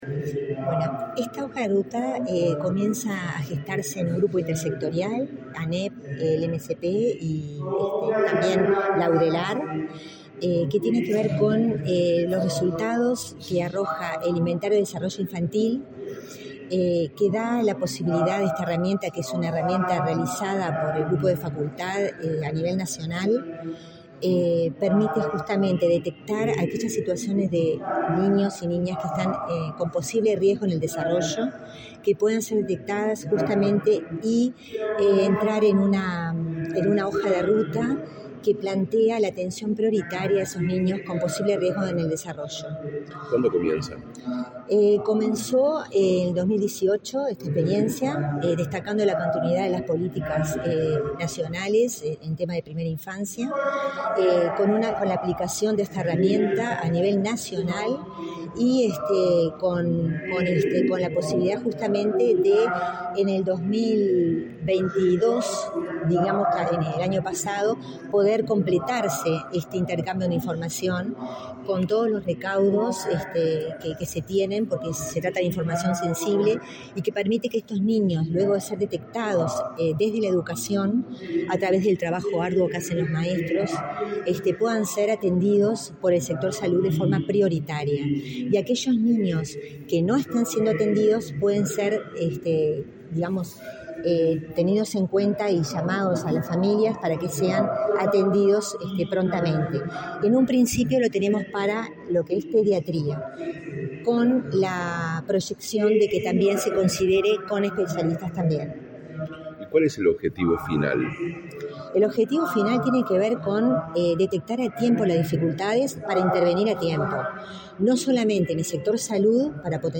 Declaraciones a la prensa de la directora de Salud Estudiantil, Carolina Ponasso